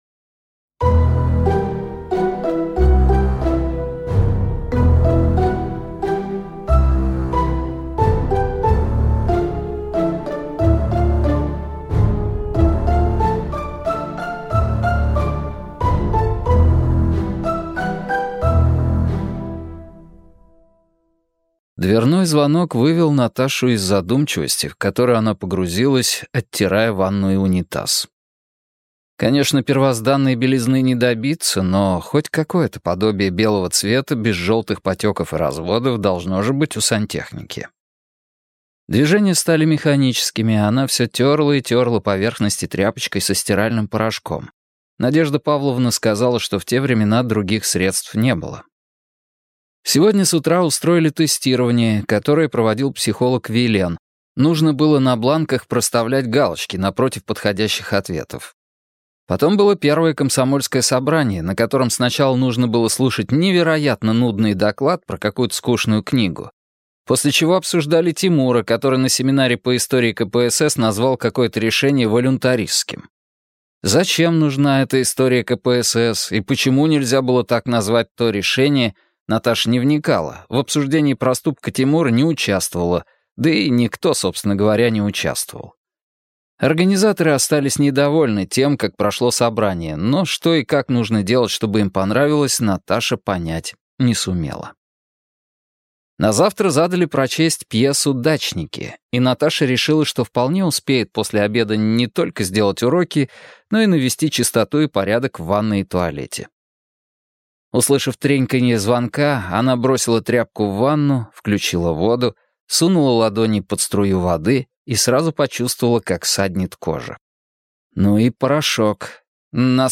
Аудиокнига Горький квест. Том 3 - купить, скачать и слушать онлайн | КнигоПоиск